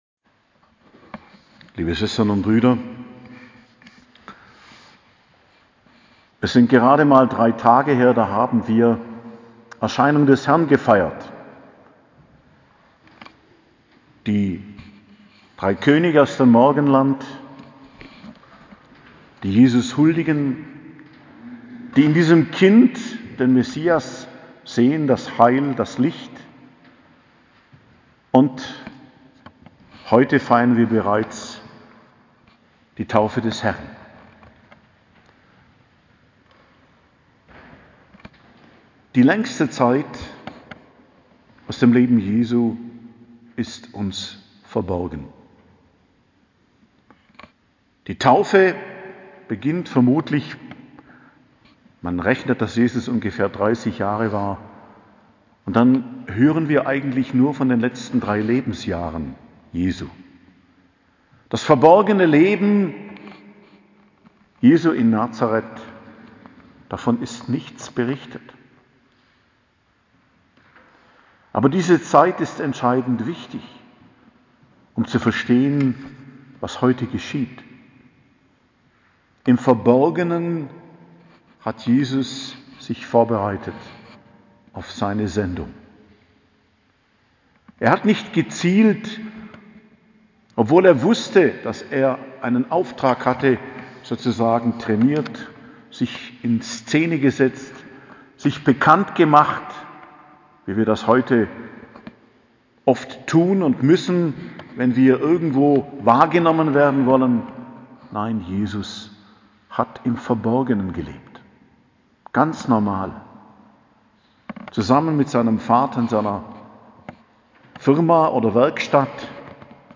Predigt zum Fest Taufe des Herrn, 9.01.2021 ~ Geistliches Zentrum Kloster Heiligkreuztal Podcast